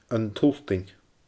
An t-Sultain (Sult.) /əN tuLdɛNʲ/